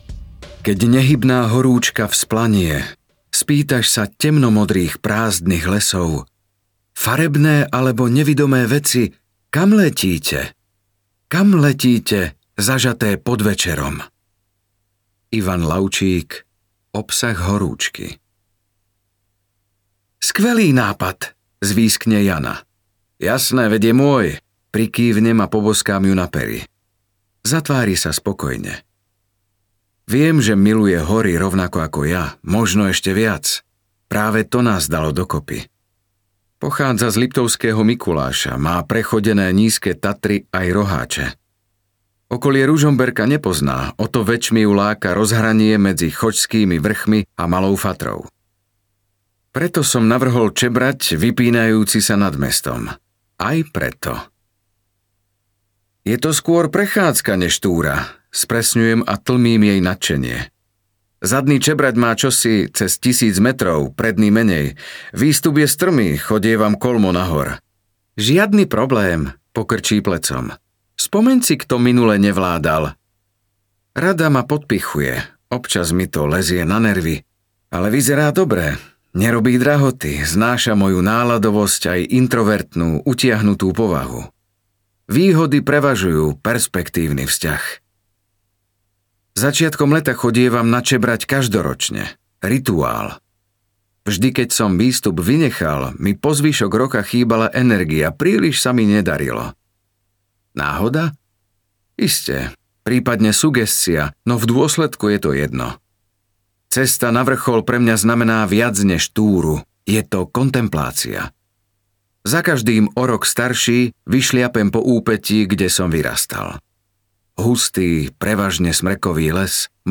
Smäd audiokniha
Ukázka z knihy